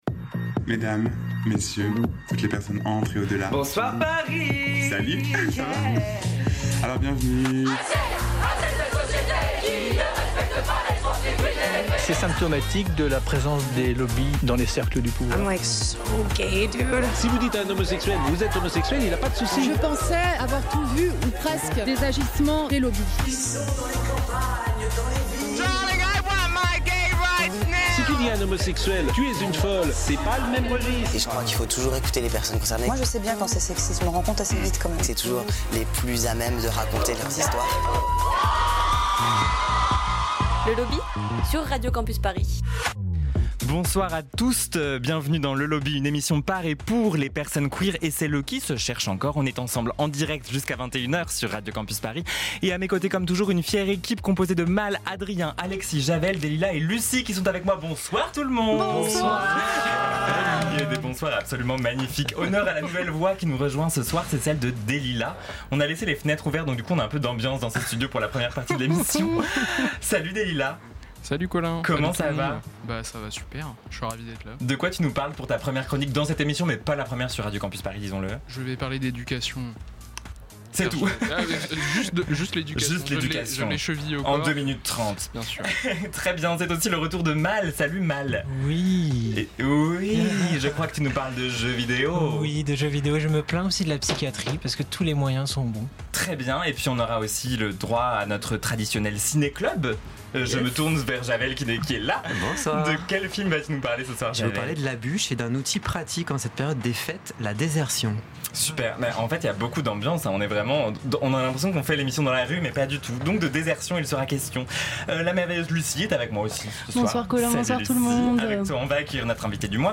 une voix envoûtante, qui prend le temps, qui impose son propre rythme, c’est un souffle puissant